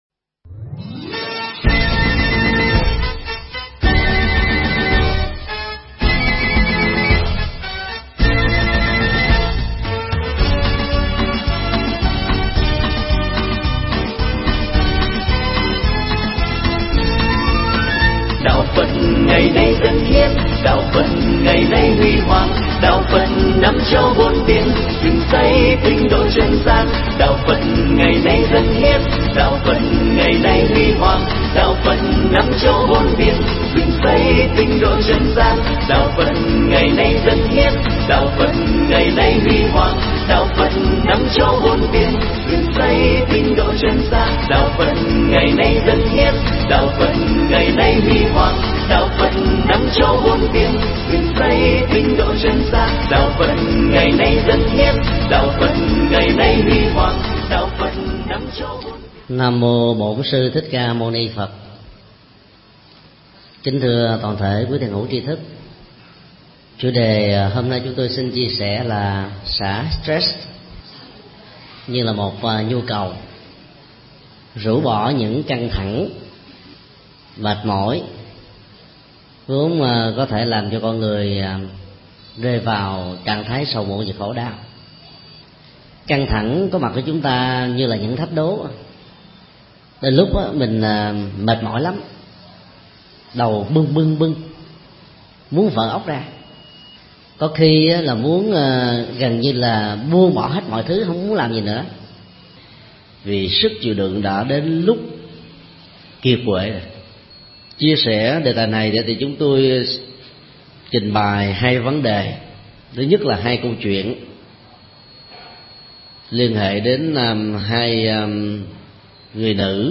Pháp thoại Xả stress
thuyết pháp tại chùa Giác Ngộ